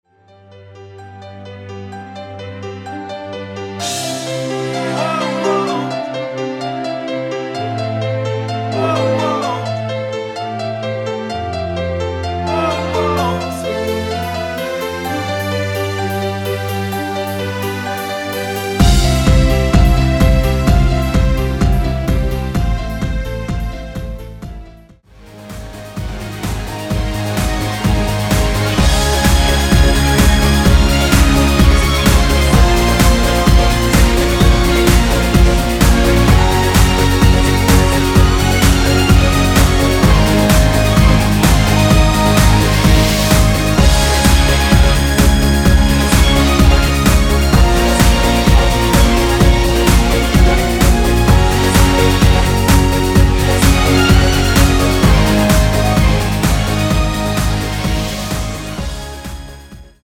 엔딩이 페이드 아웃이라 엔딩을 만들어 놓았습니다.(멜로디 MR 미리듣기 확인)
앞부분30초, 뒷부분30초씩 편집해서 올려 드리고 있습니다.
중간에 음이 끈어지고 다시 나오는 이유는